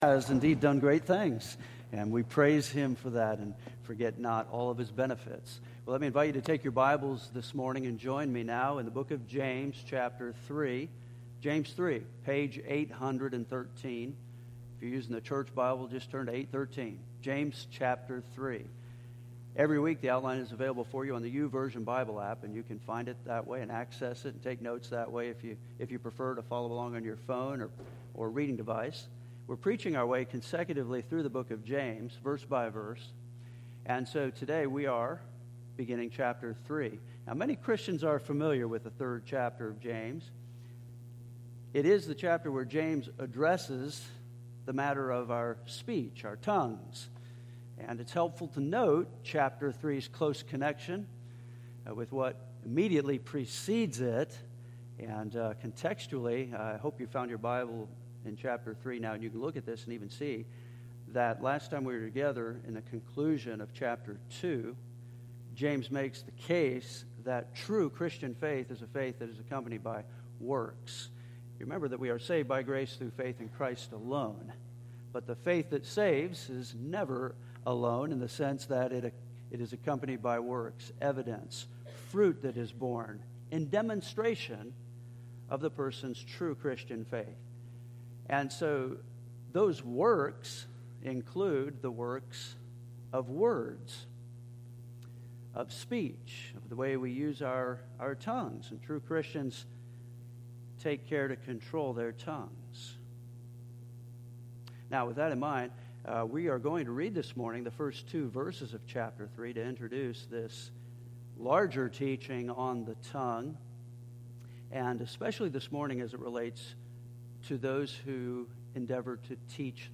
We are preaching our way consecutively through the Book of James.